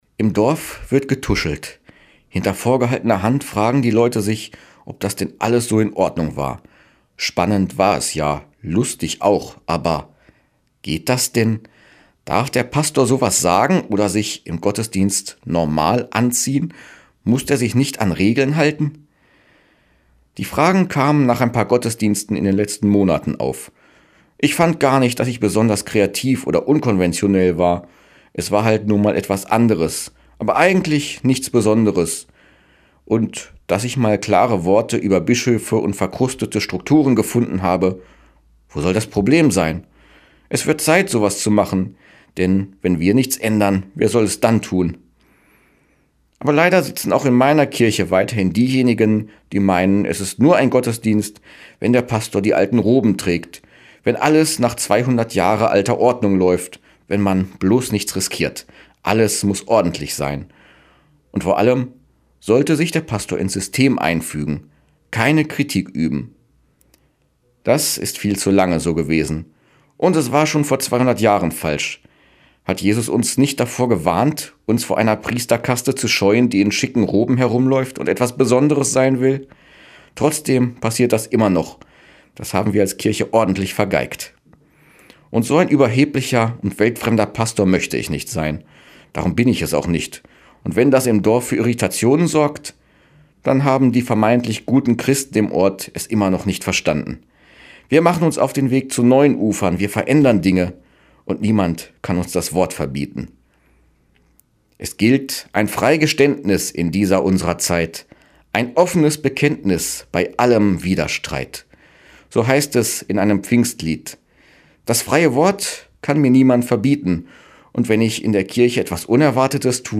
Radioandacht vom 28. Juni